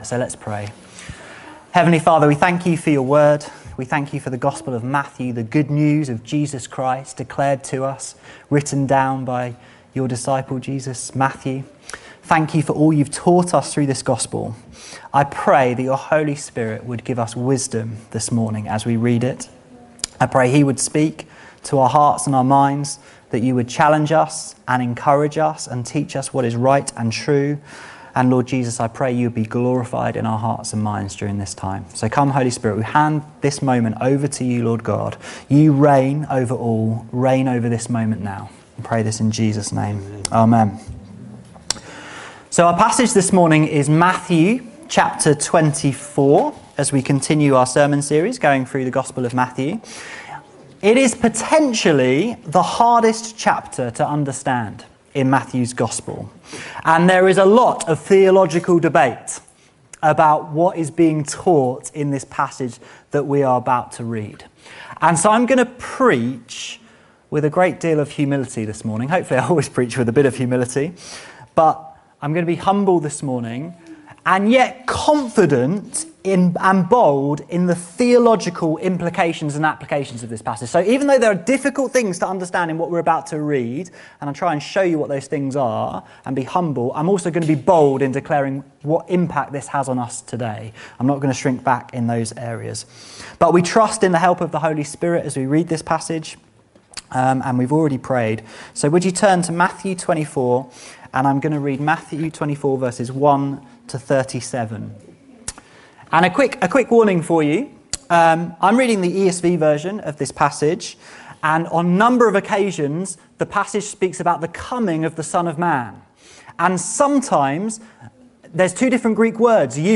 This sermon unpacks the double fulfilment of these verses.